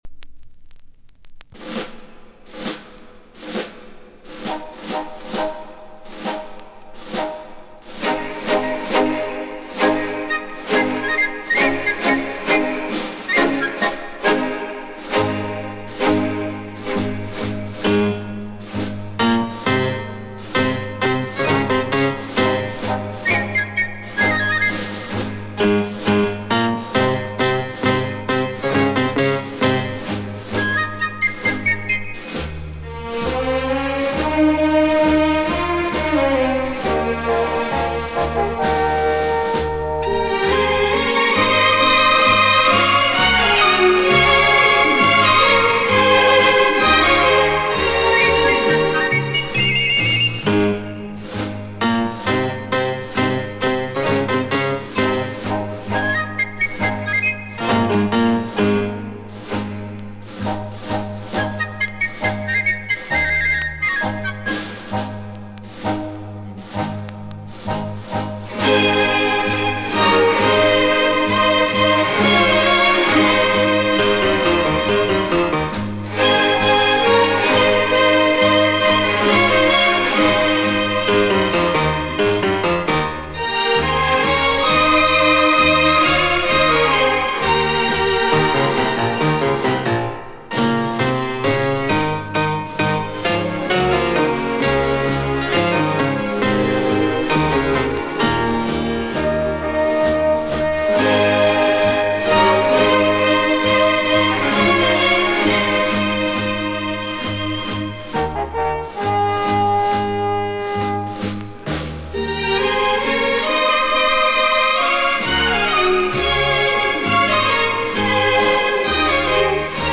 Long Play digitalizado